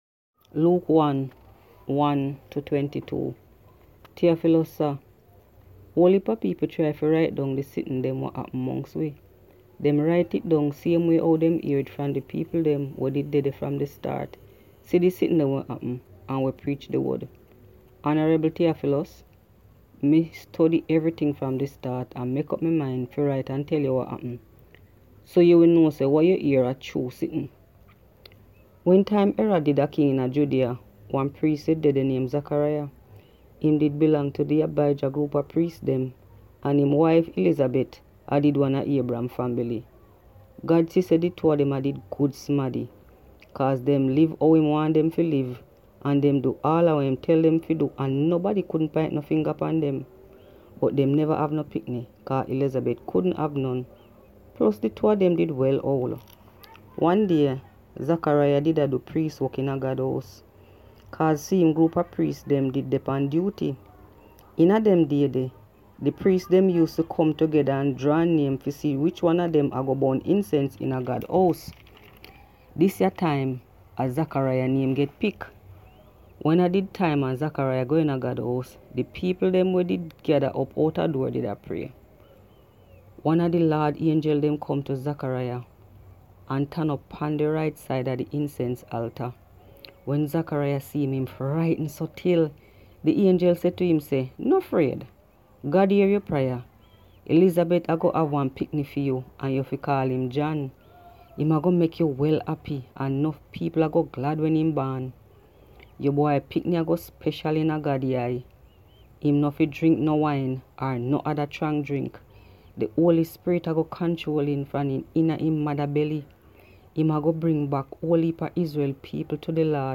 Today we present to you excerpts from the Holy Bible in the Jamaican language Patois (pronunciation patwa) or Jamaican Creole.
Patwa Bible - Luuk 1-22.mp3